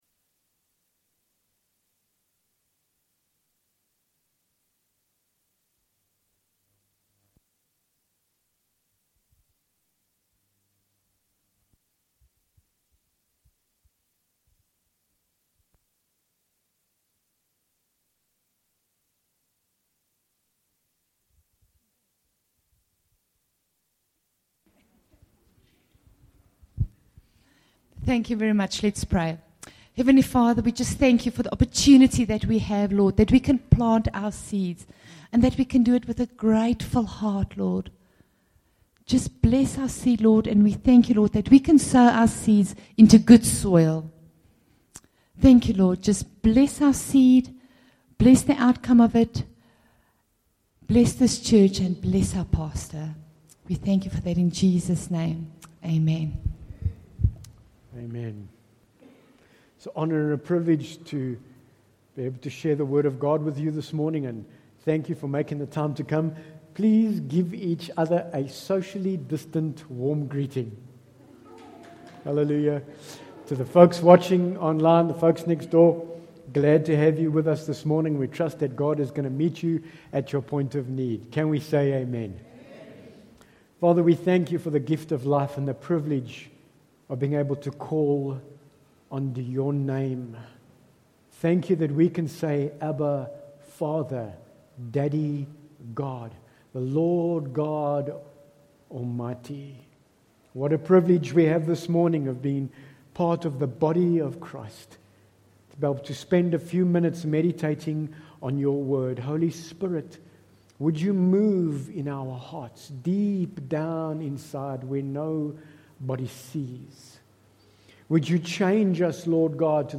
Bible Text: Psalm 99:5 | Preacher